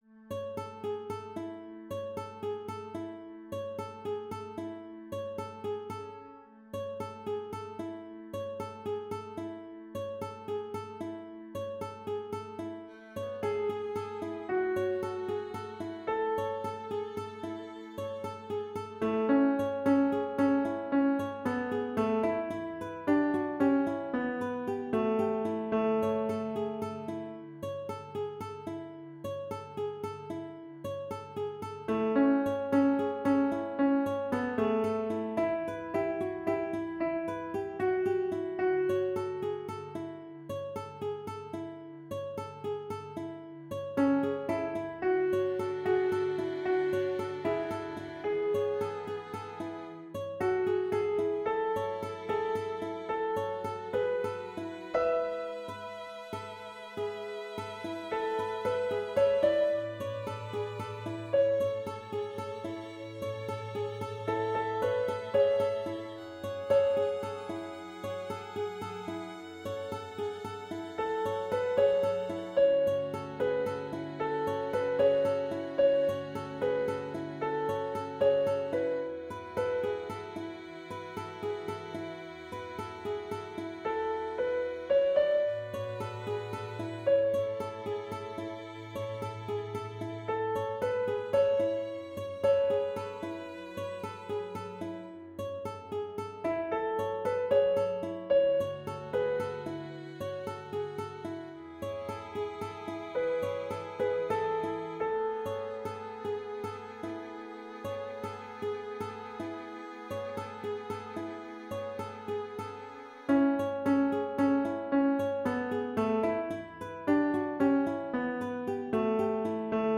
Rehearsal Tracks:
Soprano audio
hope-of-the-ages-soprano-track.mp3